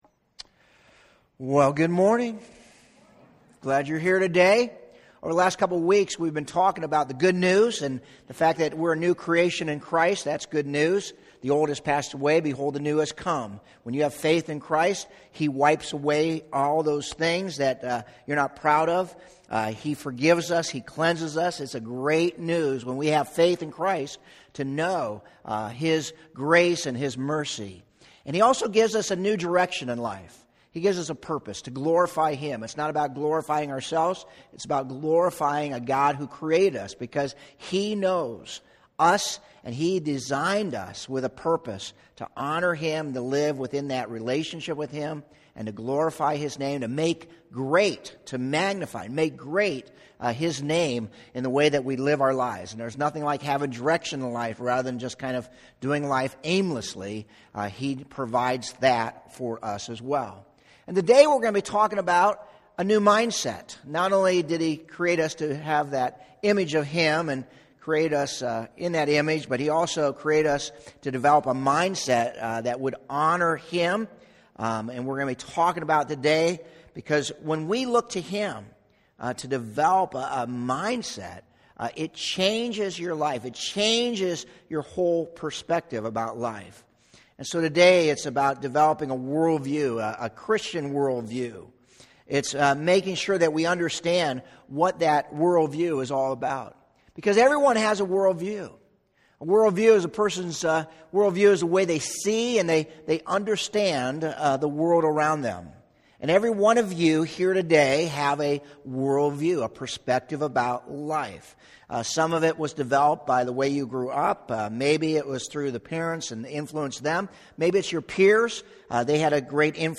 Medina Community Church Sermons